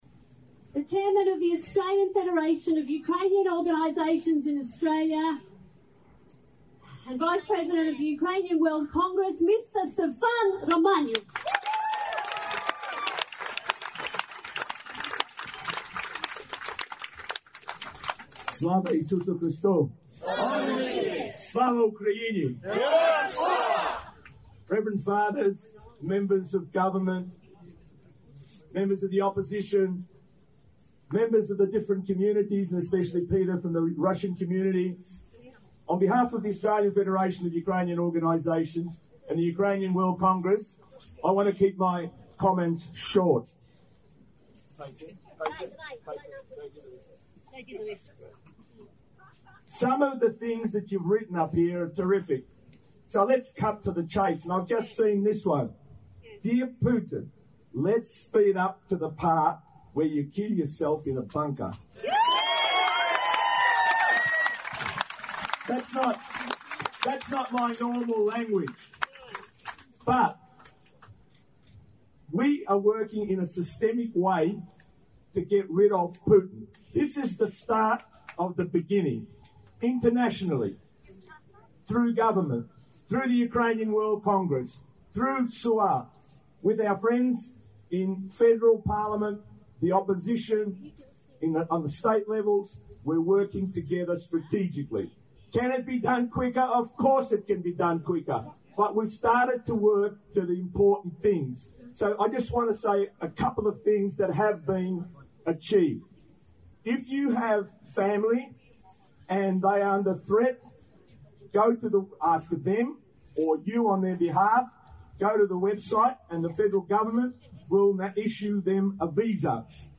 виступив на мітинґу у часі маніфестації проти вторгнення збройних сил Росії в Україну...
Anti-war protest in Melbourne against invasion of Ukraine, 27/02/2022.